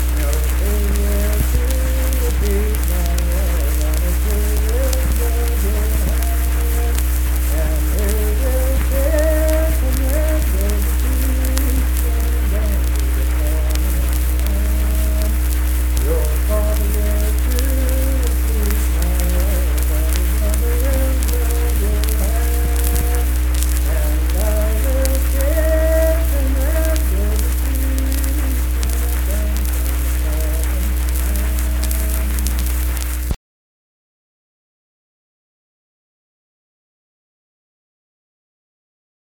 Unaccompanied vocal music
Verse-refrain 2(4w/R).
Performed in Kanawha Head, Upshur County, WV.
Voice (sung)